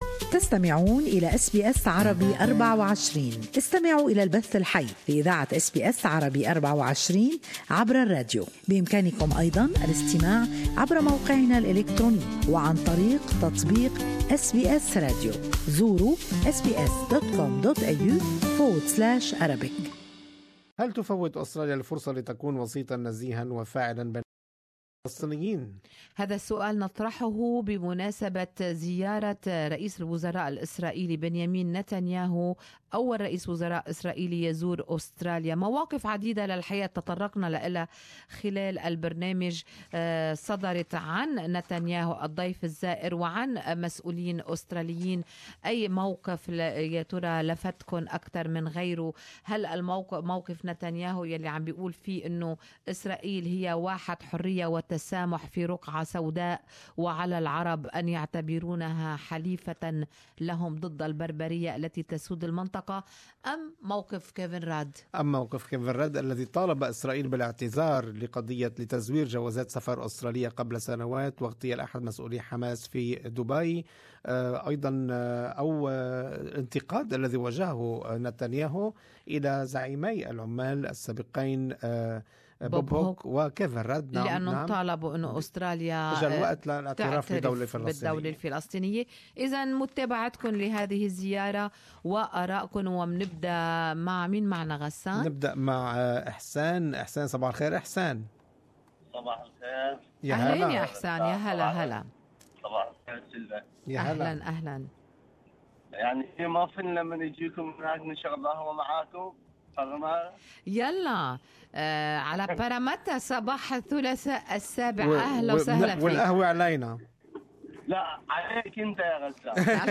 In light of Netanyahu's visit to Australia, we discuss with our listeners the role Australia plays in the Palestinian- israeli conflict.